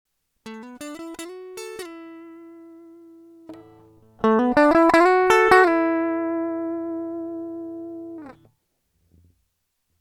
Jedoch habe ich einen sehr großen Pegelunterschied von etwas 20 db zwischen Steg- und Halstonabnehmer.
Ich hab mal eine mp3 angehängt, bei der ich denselben Lick mal auf dem Hals- und dann auf dem Stegtonabnehmer gespielt habe. Aufgenommen habe ich über mein Interface, direkt in meine DAW. Bei dem Modell handelt es sich um eine American Elite Telecaster aus dem Jahr 2019, mit noiseless Pickups.